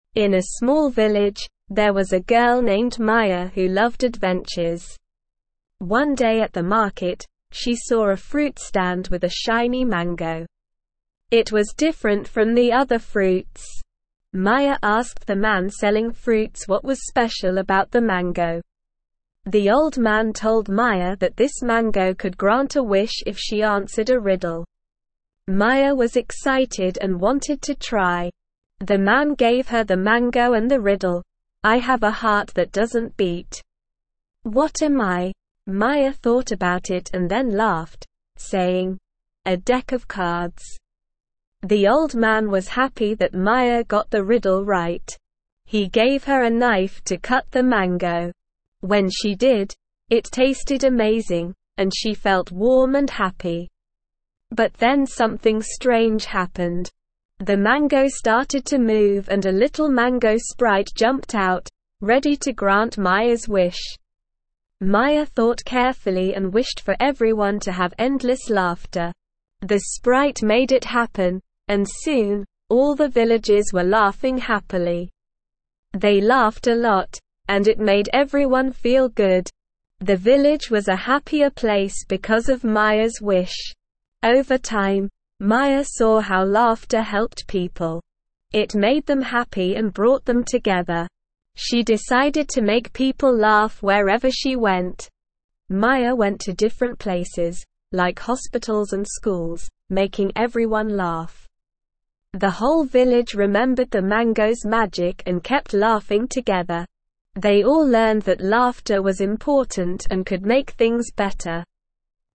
Slow
ESL-Short-Stories-for-Kids-Lower-Intermediate-SLOW-Reading-The-Mysterious-Mango.mp3